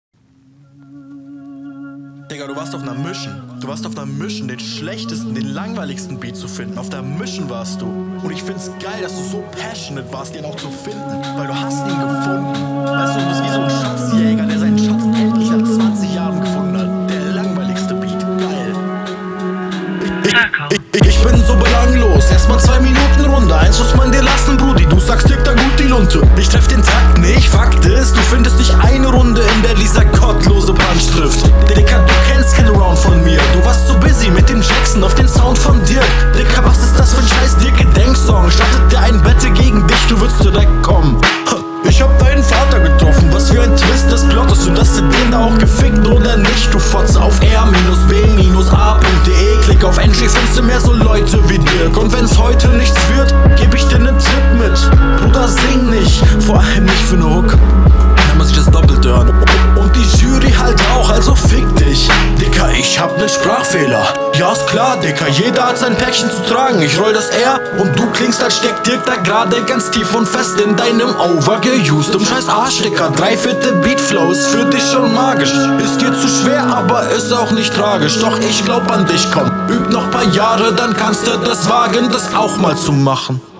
Über den Beat rumheulen ist immer das Coolste was geht.